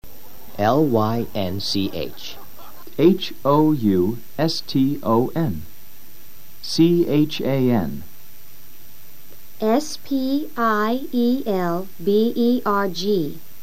Escucha al profesor deletreando los cuatro apellidos y luego intenta repetirlos.